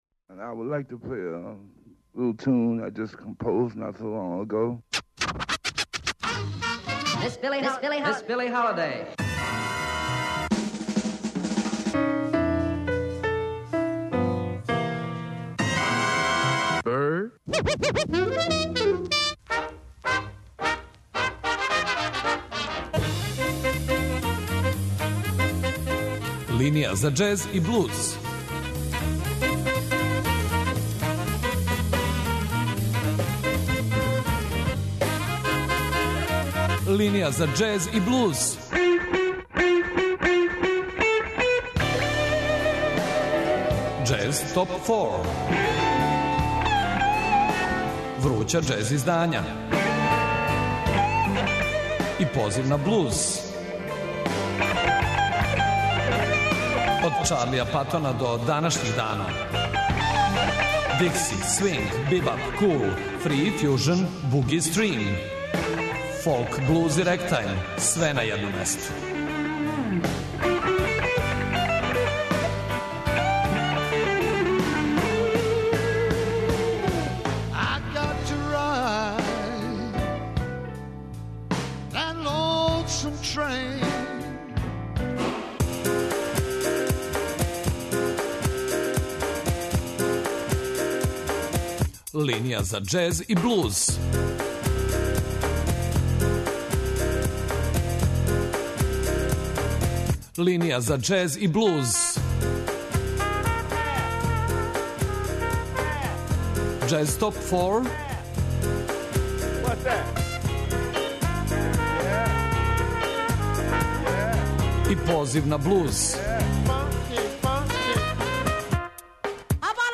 РТС :: Београд 202 :: Линија за џез и блуз